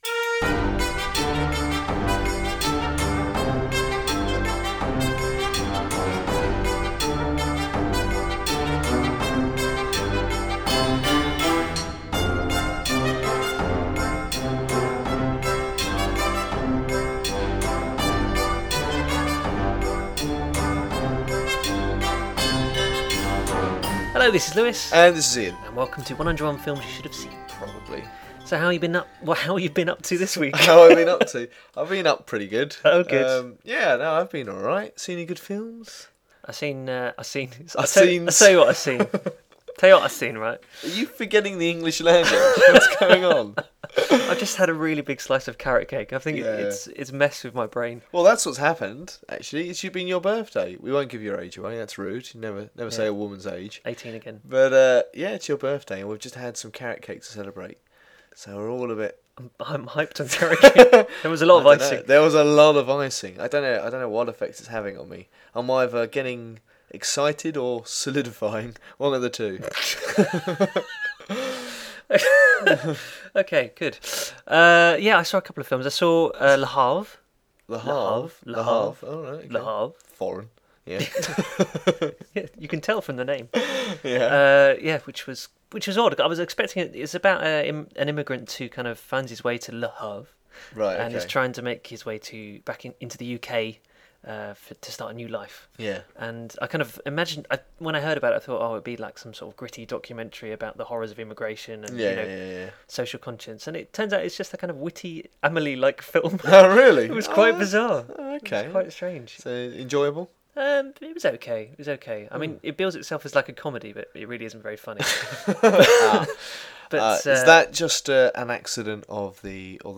How do you talk about the horrific final days of Hitler’s Germany without being too depressing, but equally without being too lighthearted? We do our best to walk the line, but inevitably this week’s podcast has a slightly more sombre feel to it than usual, along with a bit of maudlin philosophizing chucked in at the end for good measure.